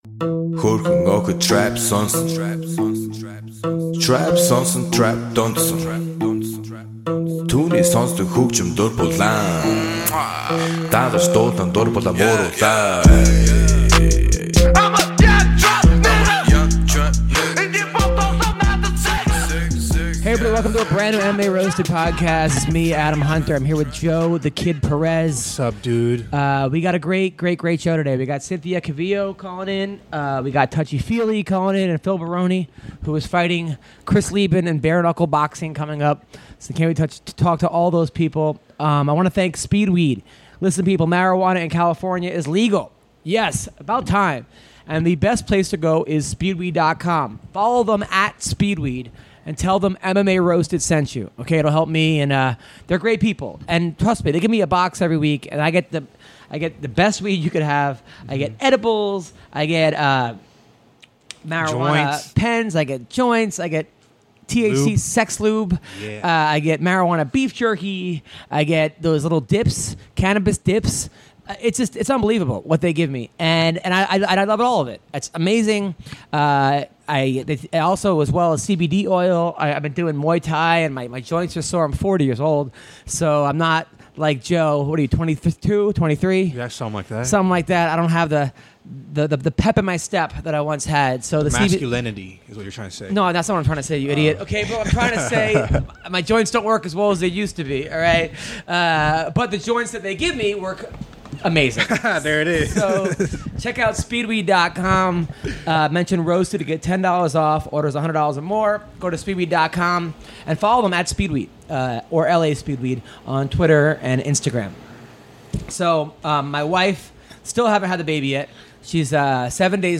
Guests Andre Fili, Cynthia Calvillo, and Phil Baroni call in!